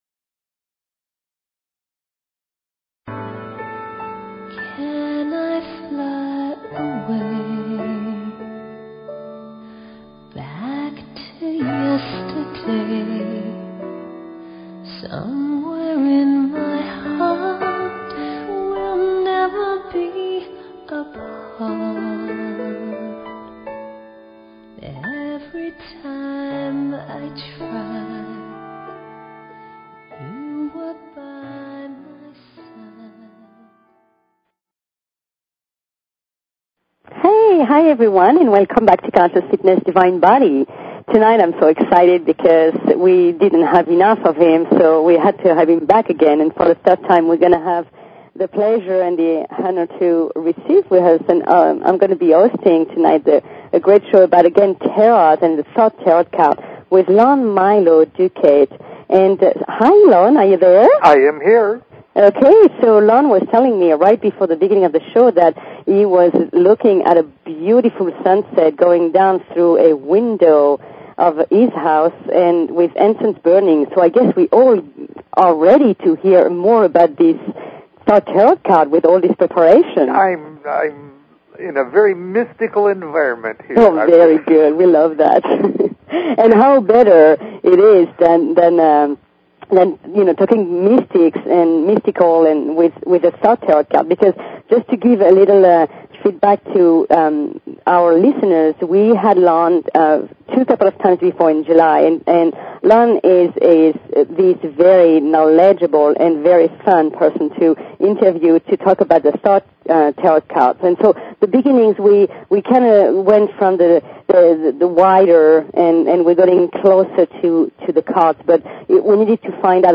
Talk Show Episode, Audio Podcast, Conscious_Fitness and Courtesy of BBS Radio on , show guests , about , categorized as